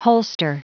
Prononciation du mot holster en anglais (fichier audio)
Prononciation du mot : holster